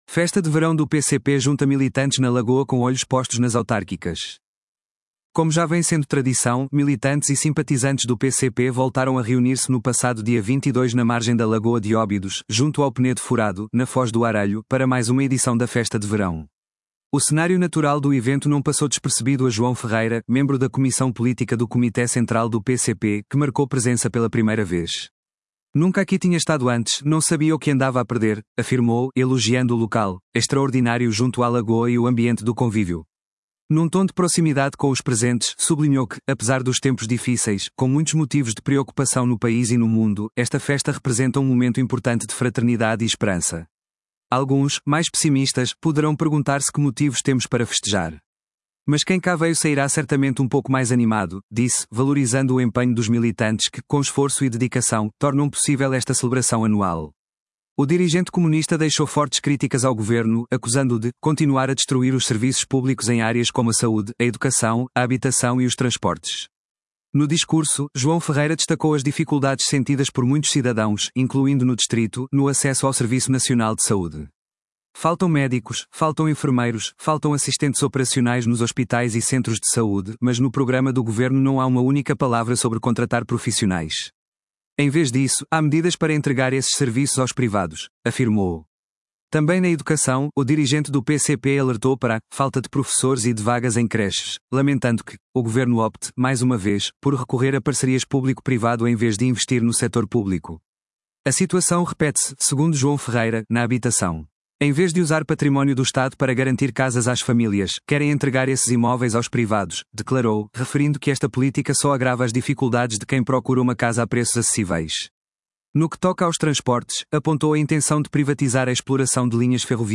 Como já vem sendo tradição, militantes e simpatizantes do PCP voltaram a reunir-se no passado dia 22 na margem da Lagoa de Óbidos, junto ao Penedo Furado, na Foz do Arelho, para mais uma edição da Festa de Verão.
João Ferreira, membro da Comissão Política do Comité Central do PCP, a discursar na Festa de Verão